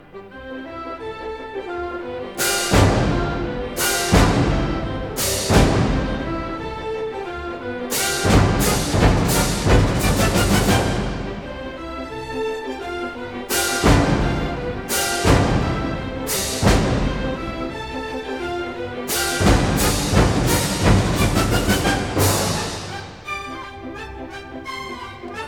Stereo recording made in April 1960 in the
Orchestral Hall, Chicago